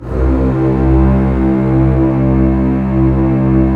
Index of /90_sSampleCDs/Roland L-CD702/VOL-1/STR_Cbs Arco/STR_Cbs2 Orchest